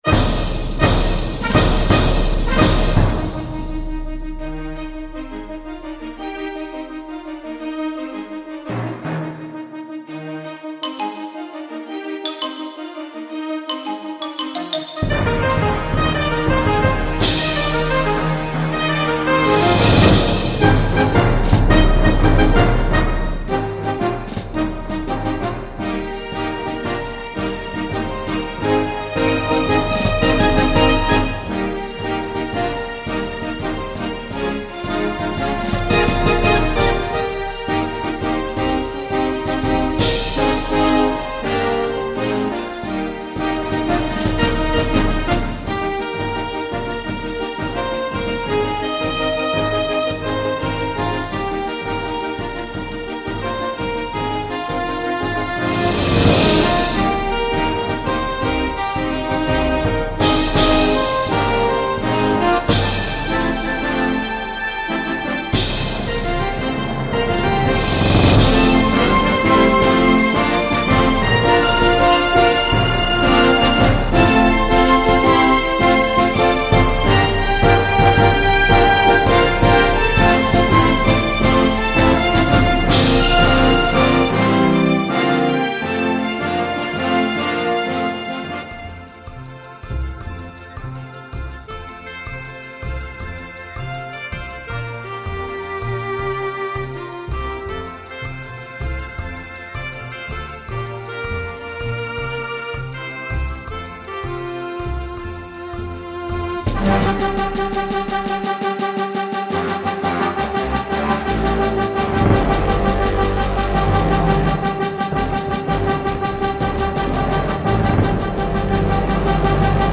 高保真和CD的低噪音 开创靓声新纪元
双电子琴有丰富的音色及独特的泛音效果，它的独奏可以和一个管弦乐队媲美。
本辑录音精细入微，逼真的现场音效给人以交响乐队般的震撼！